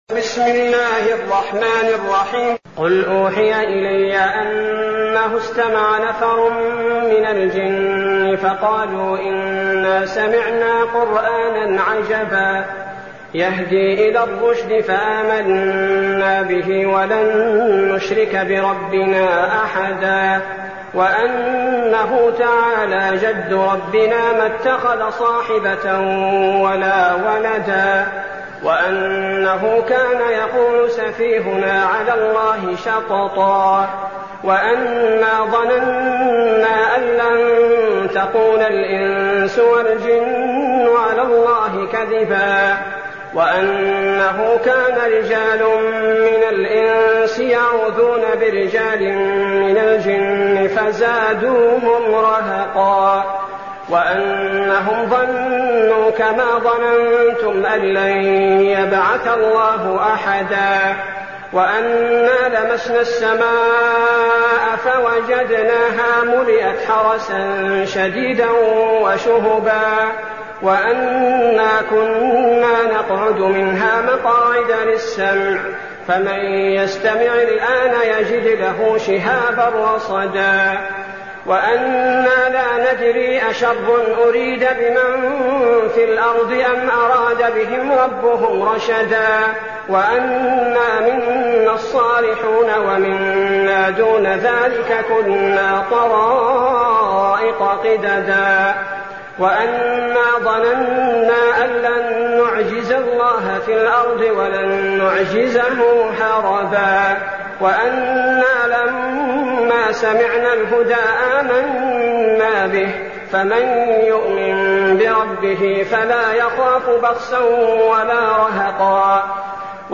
المكان: المسجد النبوي الشيخ: فضيلة الشيخ عبدالباري الثبيتي فضيلة الشيخ عبدالباري الثبيتي الجن